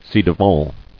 [ci-de·vant]